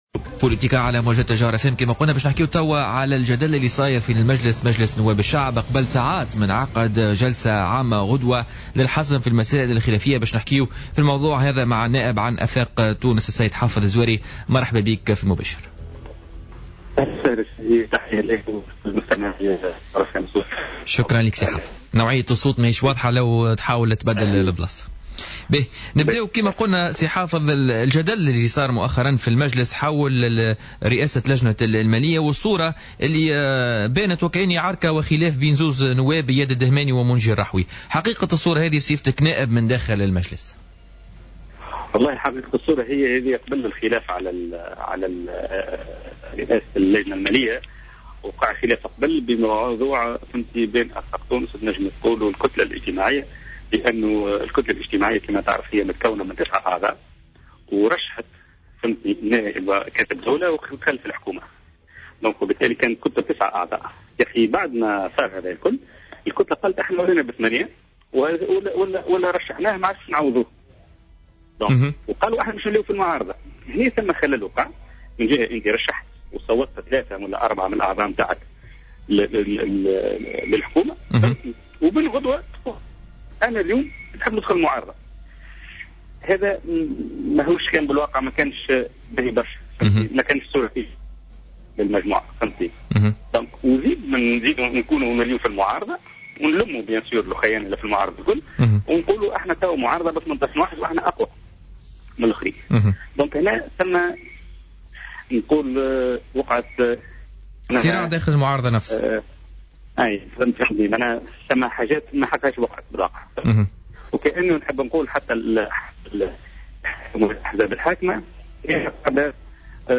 أكد النائب عن افاق تونس بمجلس الشعب حافظ الزواري في مداخلة له في برنامج بوليتيكا اليوم الخميس 26 فيفري 2015 رفضه لمقترح تبني طريقة التصويت السري للجسلة العامة المنتظر عقدها بالمجلس غدا الجمعة.